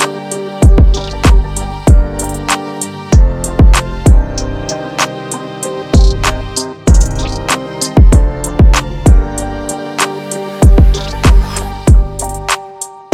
BPM: 96 Mood: chill, relaxed Format: MP3 + WAV
beats , drill , hip-hop , rap , trap